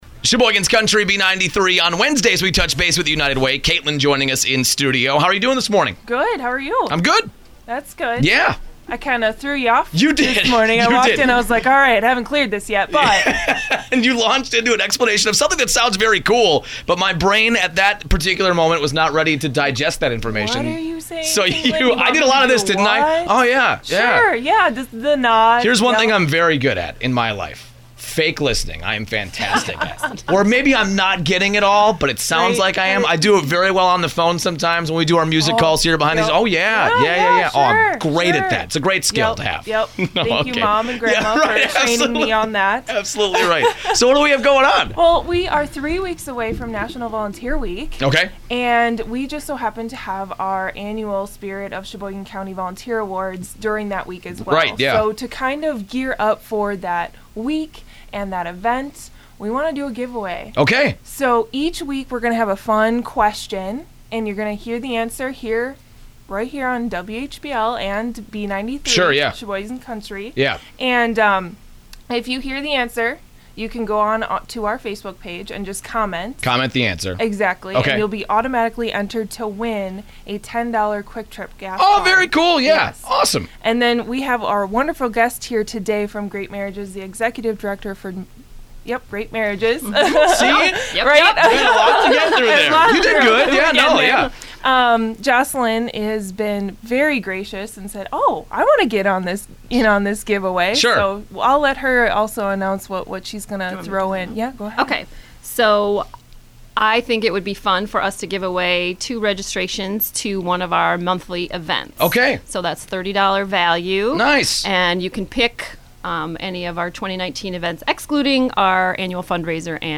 Great Marriages - Radio Spot